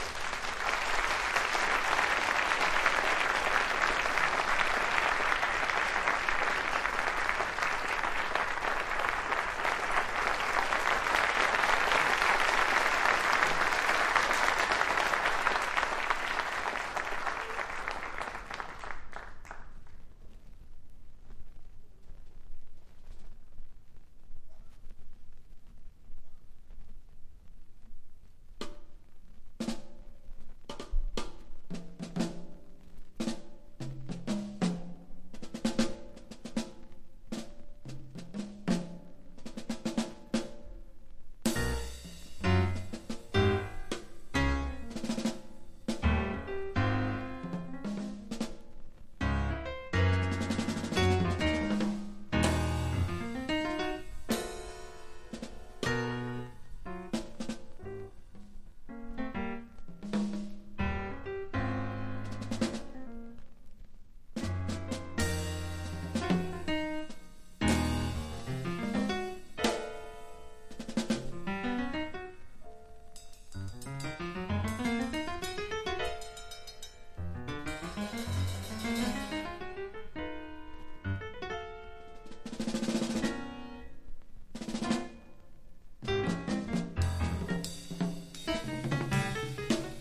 # 和ジャズ# FREE / SPIRITUAL